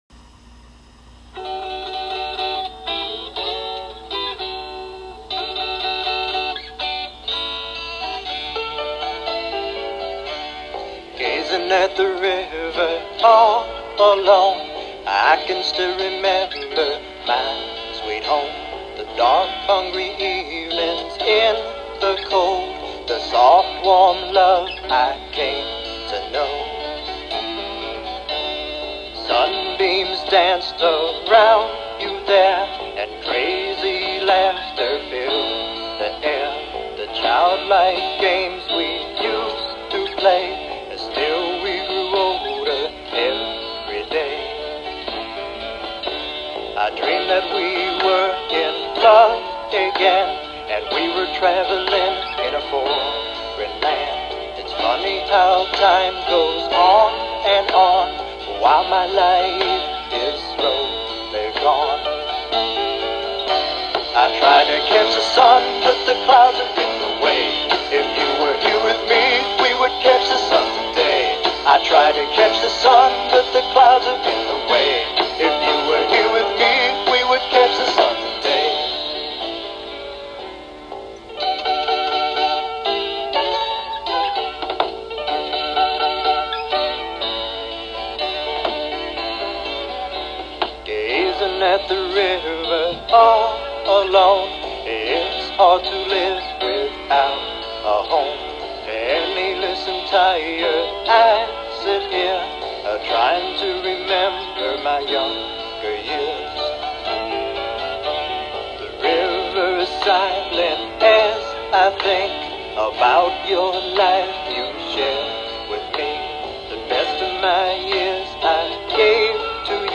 This devotional sermon offers comfort and encouragement to anyone wrestling with grief, reminding them of God's sustaining presence.